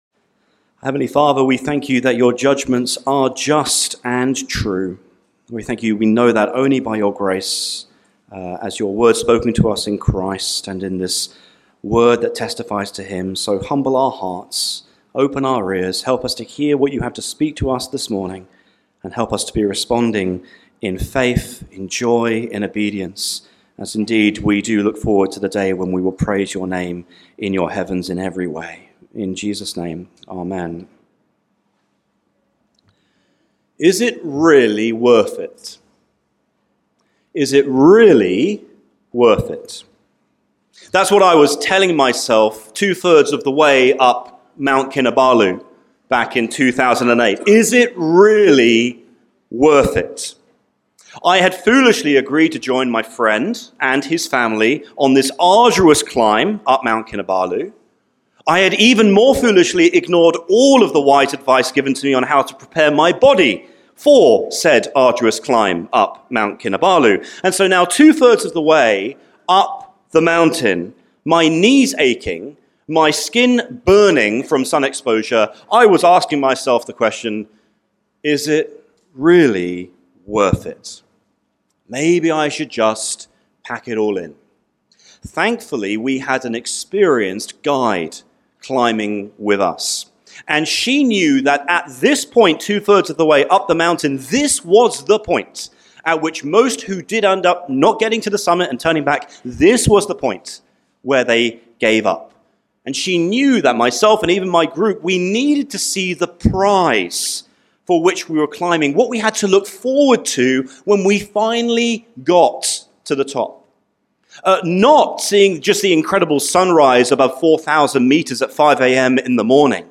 Topics: Sermon